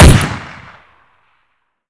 Counter-Strike Pack / Original Sounds / weapons
mp5-1.wav